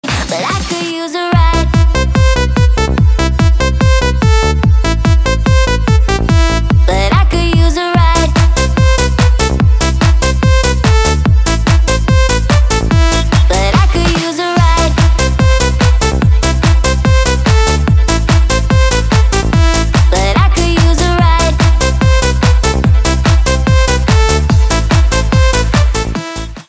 Ритмичный звучный рингтон 2024
ритмичный звучный рингтон